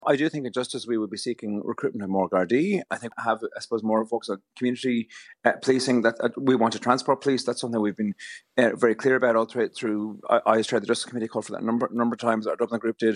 Junior Transport Minister and Kildare North TD James Lawless wouldn't be drawn on it, but says policing is a key focus.